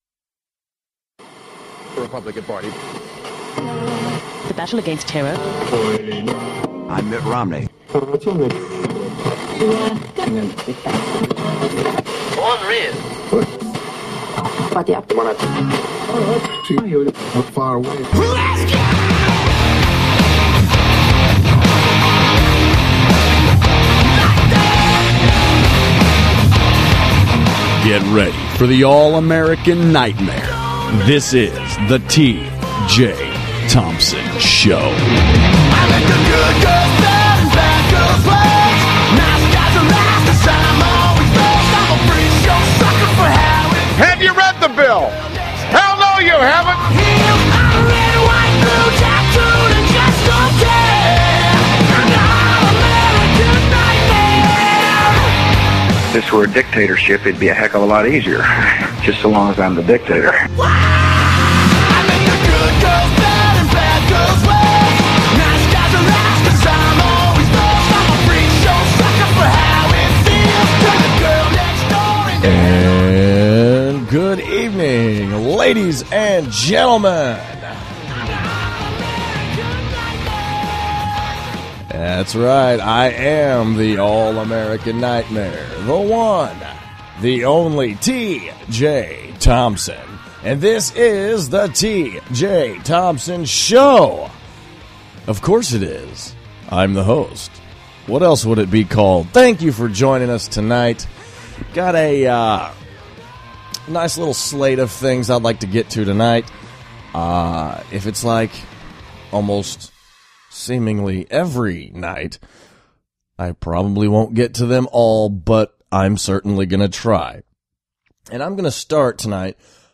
Wonder who does the beeping out of the profanities?